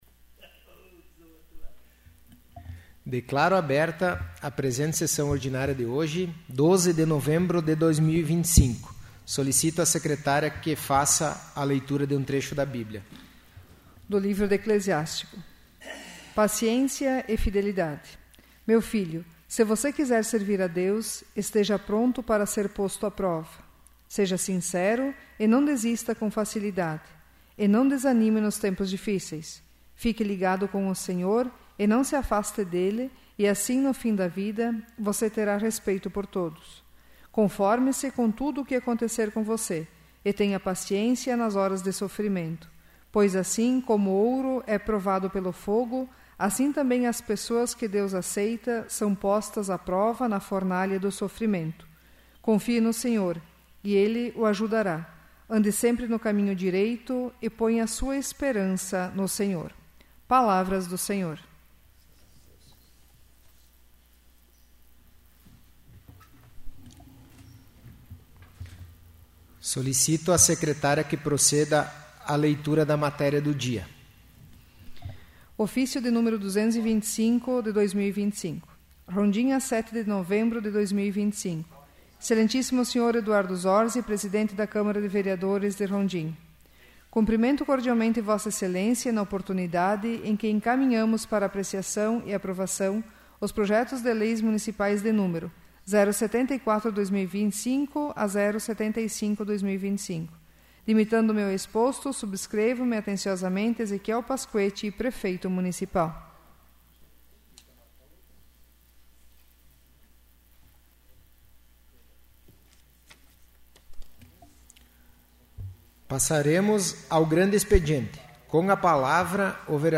'áudio da sessão do dia 29/01/2026'